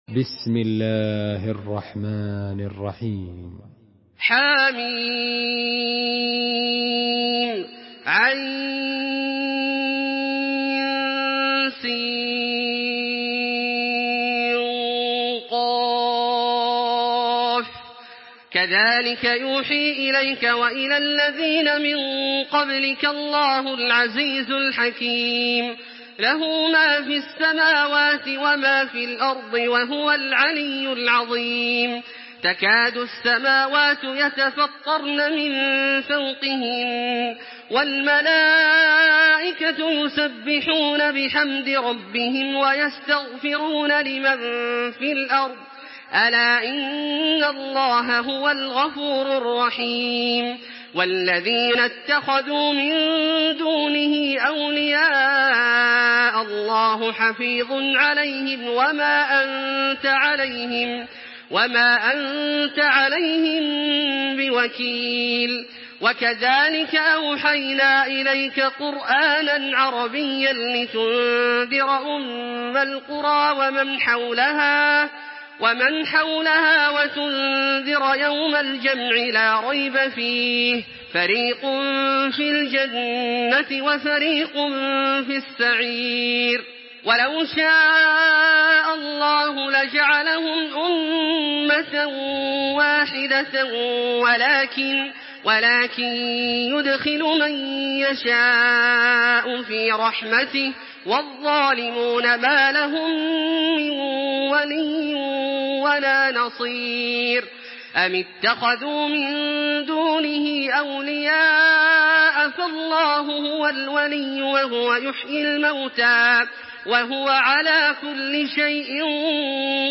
Surah আশ-শূরা MP3 in the Voice of Makkah Taraweeh 1426 in Hafs Narration
Murattal Hafs An Asim